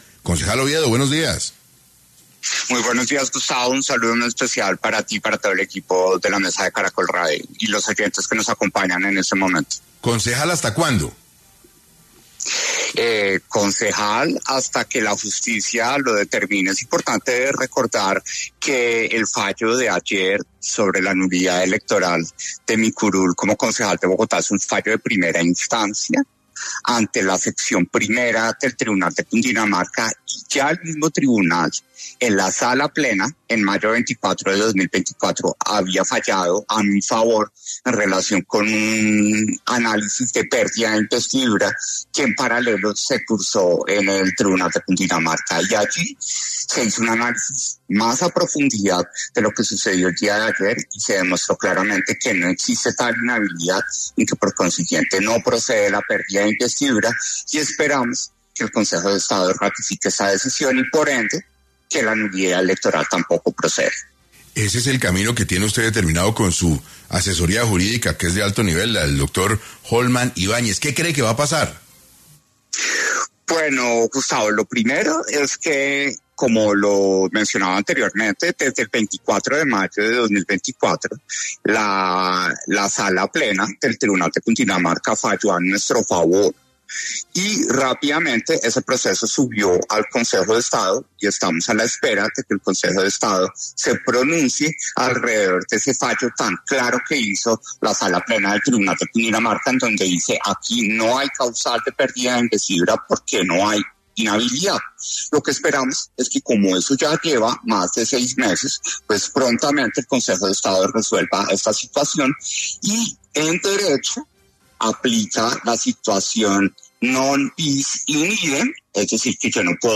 En Caracol Radio estuvo Juan Daniel Oviedo, concejal de Bogotá, reiterando su intención de llegar a la Presidencia de Colombia